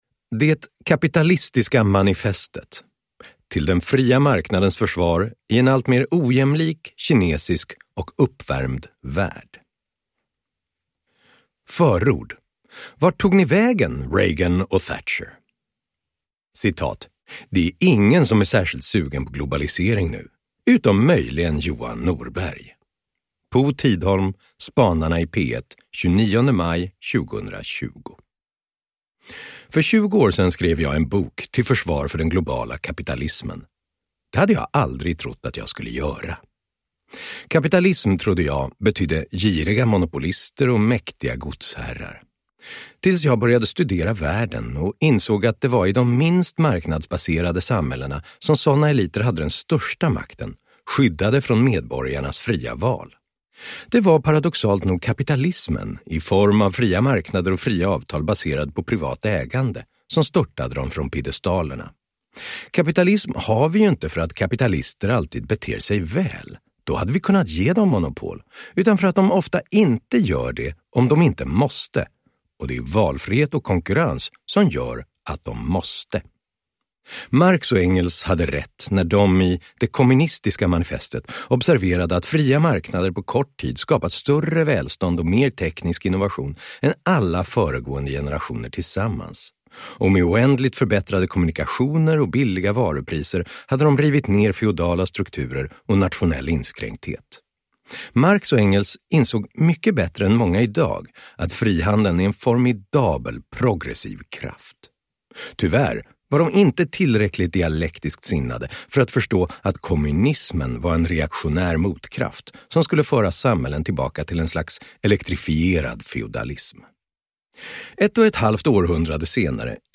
Det kapitalistiska manifestet (ljudbok) av Johan Norberg